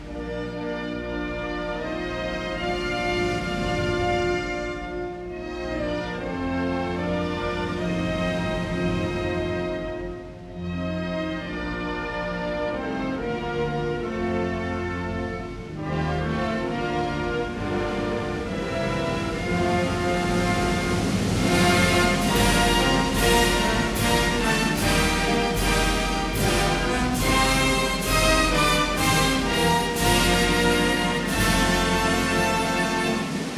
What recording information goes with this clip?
a 1958 stereo recording